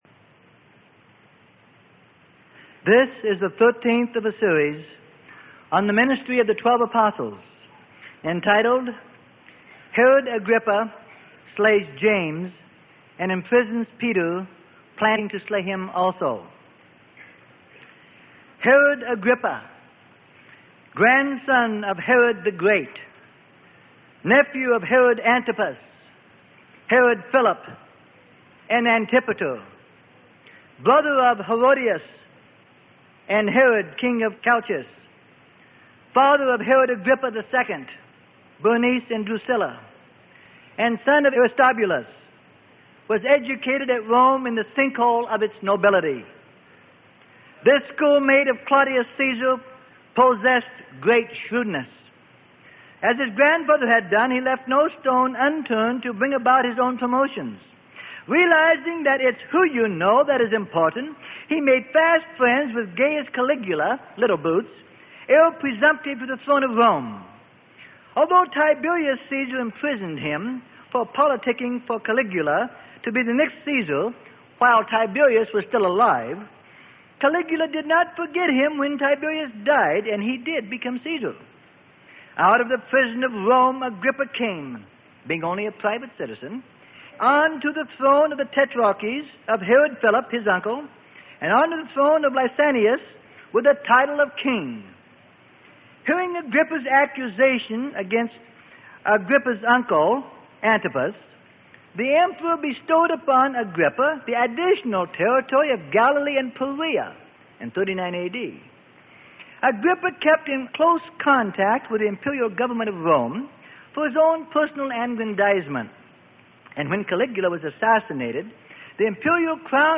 Sermon: The Acts Of The Apostles - Part 13 Of 13.